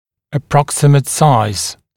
[ə’prɔksɪmət saɪz][э’проксимэт сайз]примерный размер